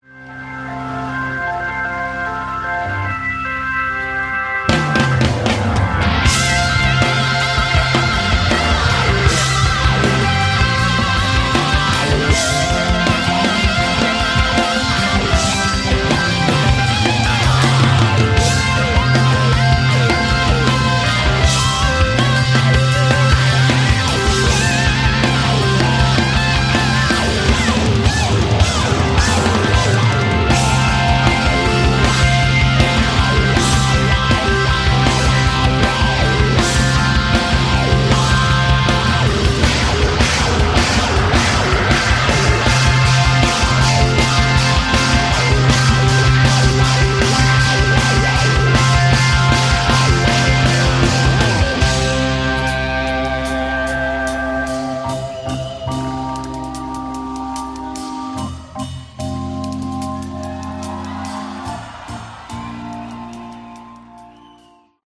Category: Classic Hard Rock
vocals
guitar
keyboards
bass
drums